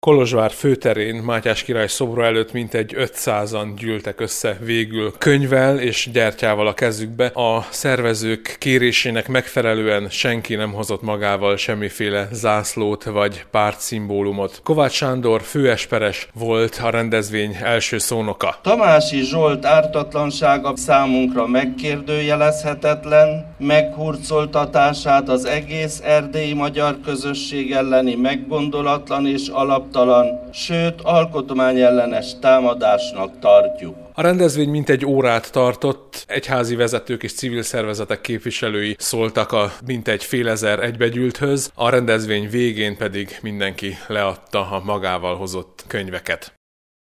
Reményik Sándor Templom és iskola című versének közös felolvasásával, a miatyánkkal és a himnusszal ért véget a kolozsvári tüntetés.